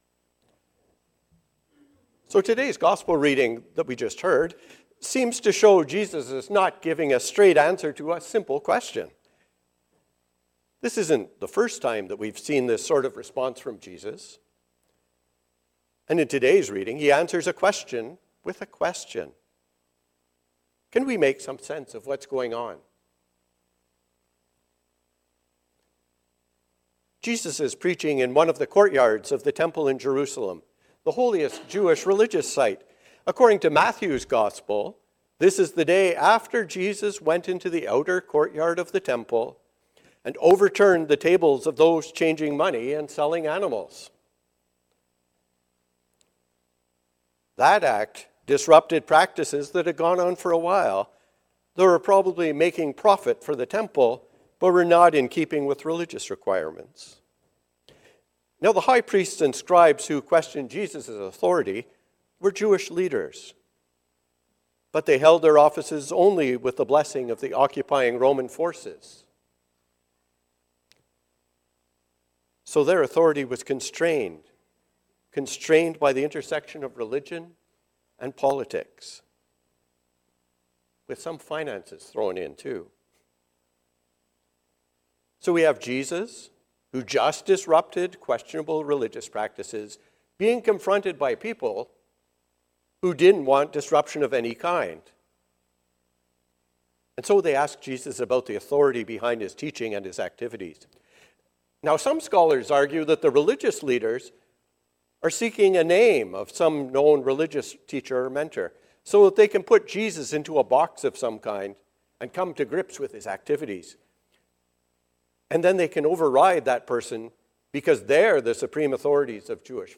More than words. A sermon on Matthew 21:23-32 on the occasion of Orange Shirt Sunday